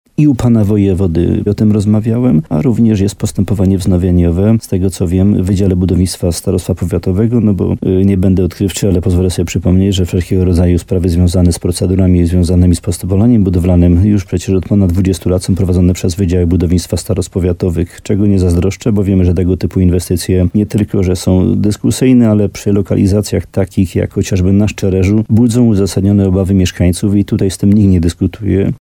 – Równocześnie u wojewody prowadzone jest postępowanie, które zostało wszczęte na wniosek mieszkańców – mówi Jan Dziedzina, wójt gminy Łącko.